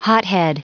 Prononciation du mot hothead en anglais (fichier audio)
Prononciation du mot : hothead